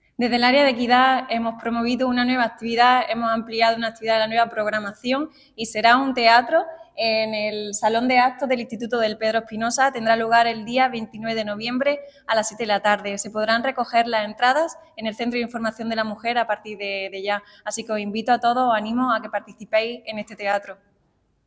La concejal delegada de Equidad del Ayuntamiento de Antequera, María Sierras, informa del próximo desarrollo de una nueva actividad enmarcada en el contexto de la conmemoración del Día Internacional de Eliminación de la Violencia contra la Mujer.
Cortes de voz